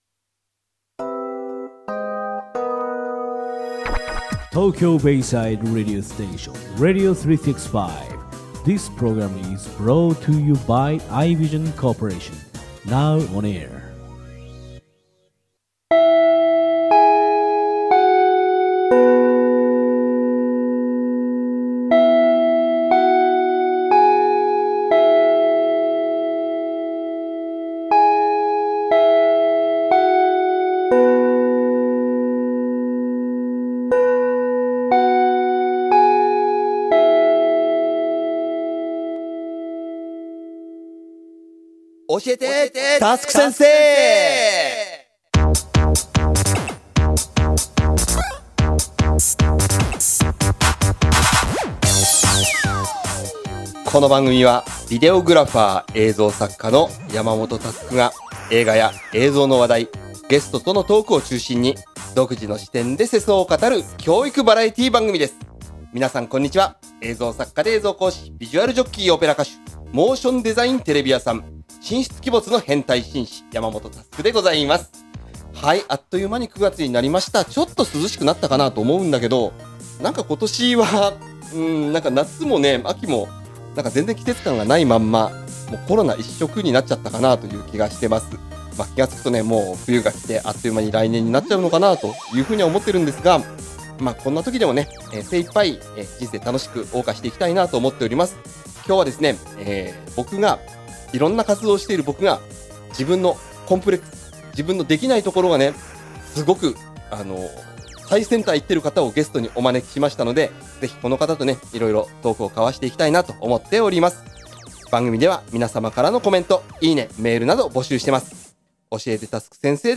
コーナー1：クリエイターズトーク